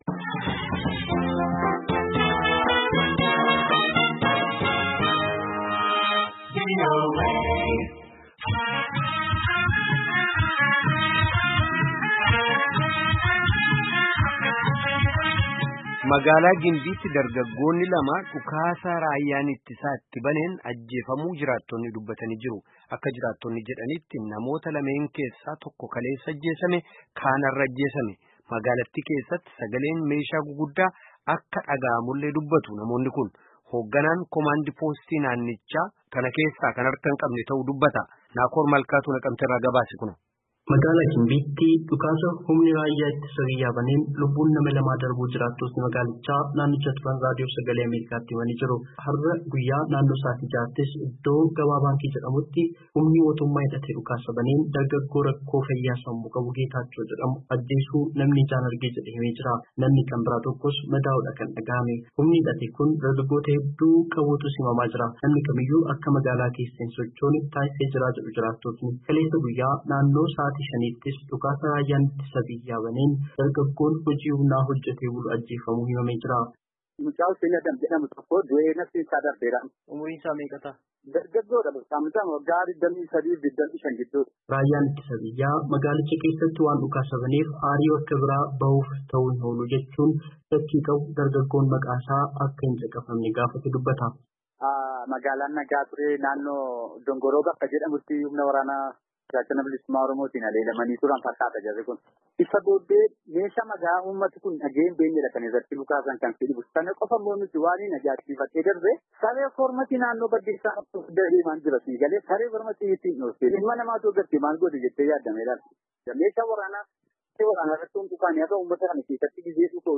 Gabaasaa
Naqamtee irraa erge caqasaa.